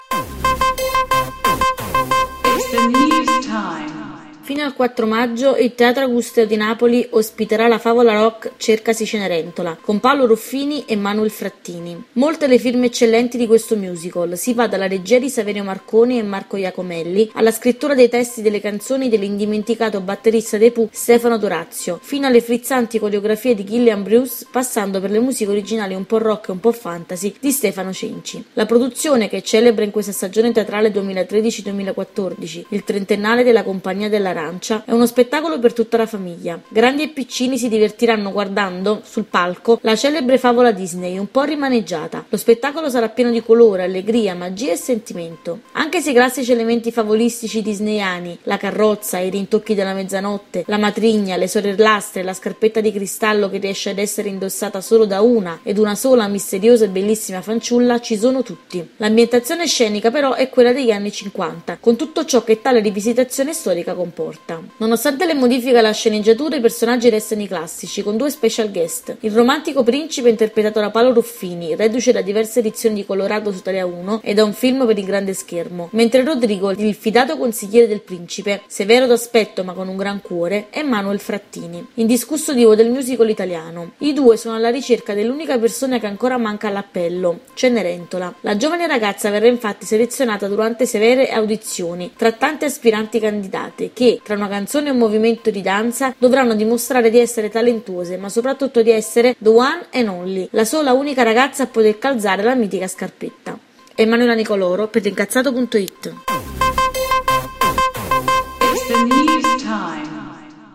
Cercasi Cenerentola, la favola rock al Teatro Augusteo
musiche originali un pò rock un pò fantasy